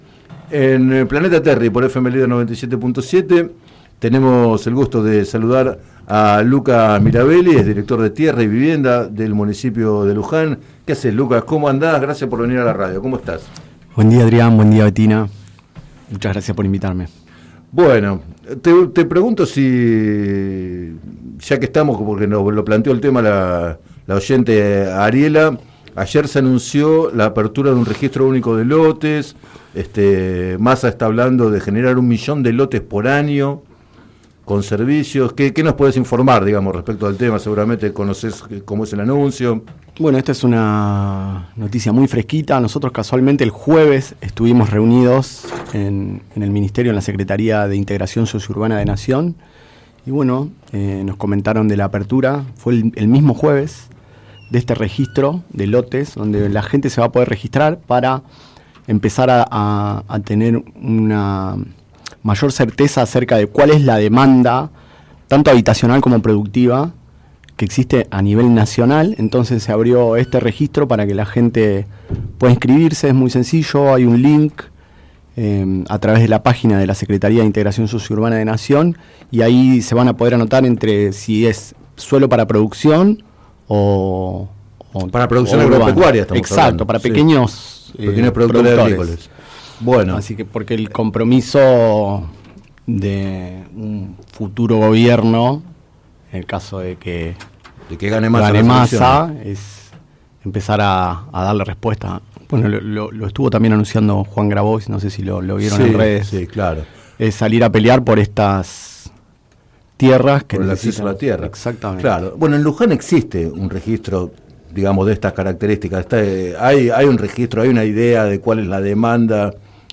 En declaraciones al programa Planeta Terri de FM Líder 97.7, Lucas Mirabelli, director de Tierra y Vivienda, explicó que los nuevos loteos también serían en terrenos del Estado Nacional y que podrían acceder al beneficio las personas que ya están inscriptas y otras que lo hagan en adelante.